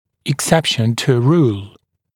[ɪk’sepʃn tu ə ruːl][ик’сэпшн ту э ру:л]исключение из правила